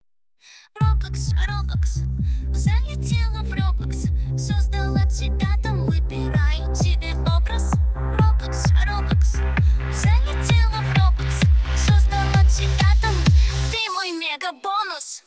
Ремикс